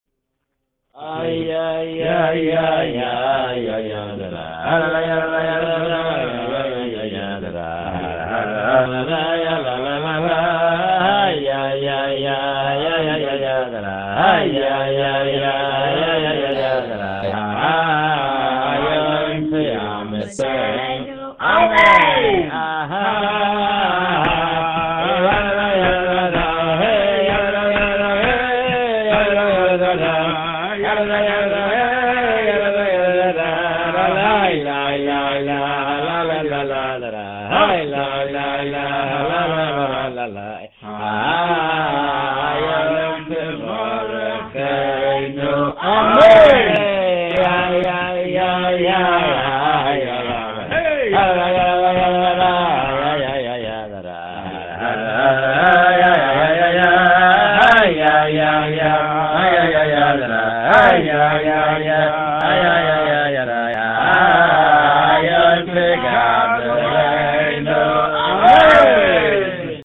Note: The program used to record this nigun turns out to be awful, but this is the track I was able to prepare in time.
Please forgive the recording quality!
This version of the famous and joyous song at the end of Rosh Hashanah musaf uses a standard Chabad nigun. The words of the piyut wish for us all that the day of Rosh Hashanah (and the effect of our prayers) be a day of blessing, joy and life.